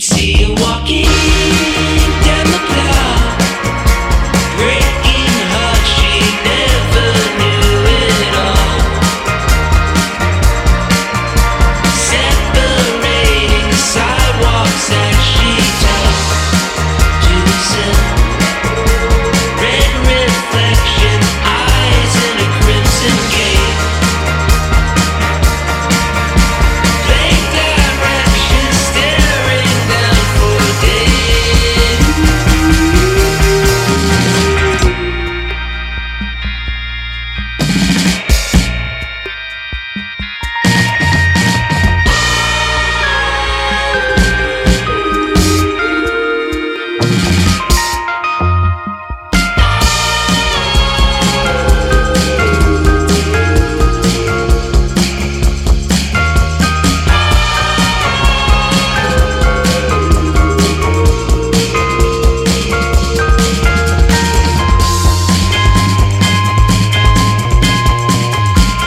ROCK / 90''S～ / INDIE POP / DREAM POP